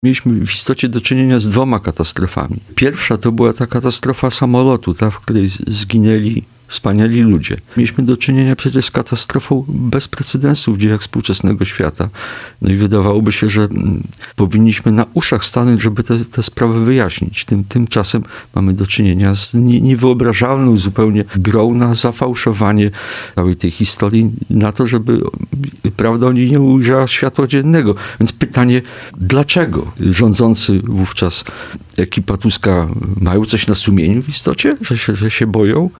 Wspominał na naszej antenie poseł Jacek Świat (PiS), który w katastrofie samolotu pod Smoleńskiem stracił żonę, Aleksandrę Natalii-Świat.